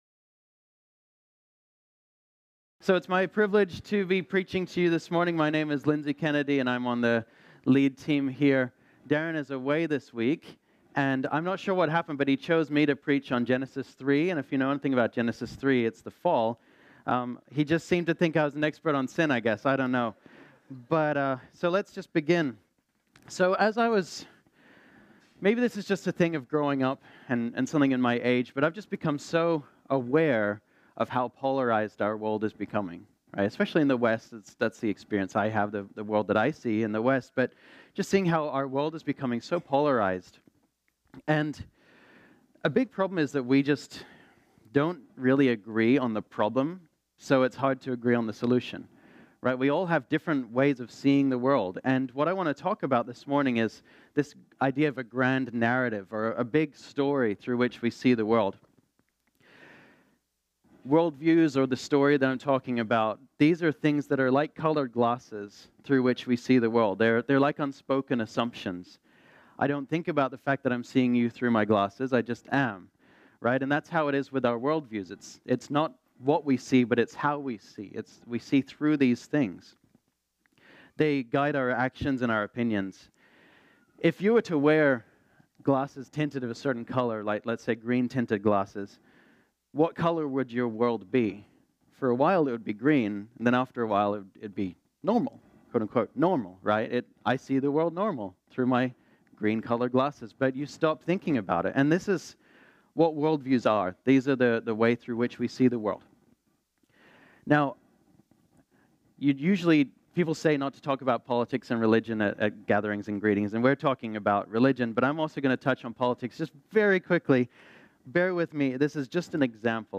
This sermon was originally preached on Sunday, January 20, 2019.